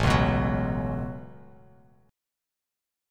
A6add9 chord